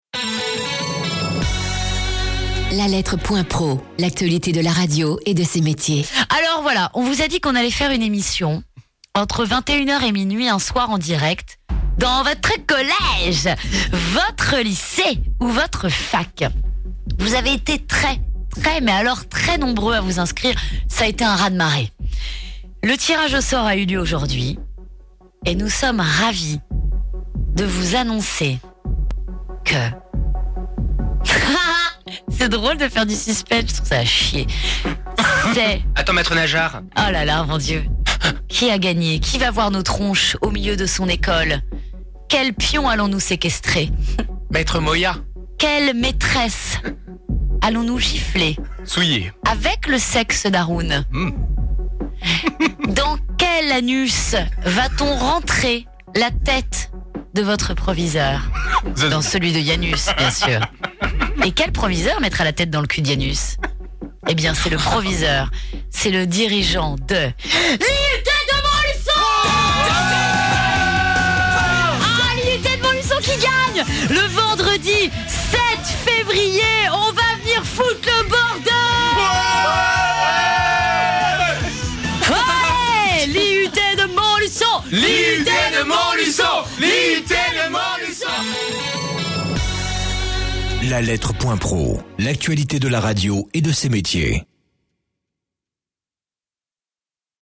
Ecoutez l'annonce de l'établissement retenu (oreilles chastes s'abstenir) ENORA_MONTLUON.mp3 (3.72 Mo)